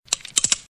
• GUN COCKING THREE TIMES.mp3
gun_cocking_three_times_ei8.wav